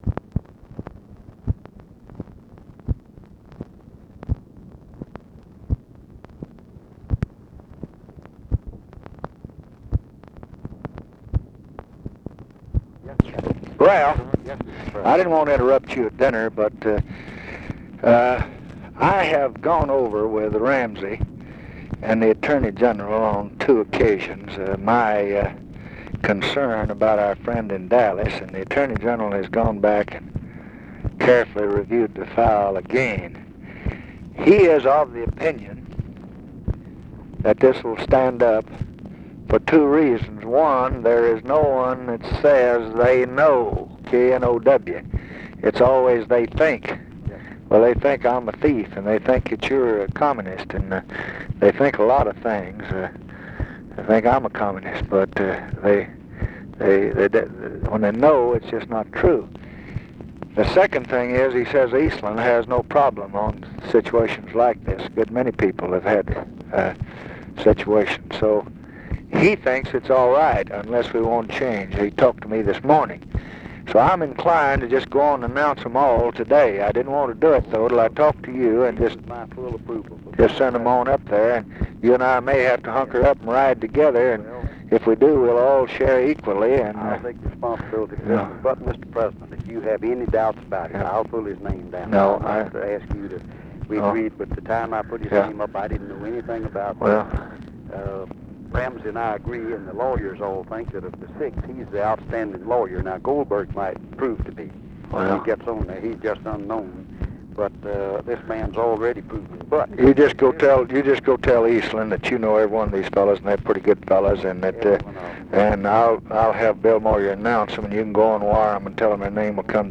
Conversation with RALPH YARBOROUGH, June 28, 1966
Secret White House Tapes